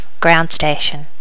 Additional sounds, some clean up but still need to do click removal on the majority.
ground station.wav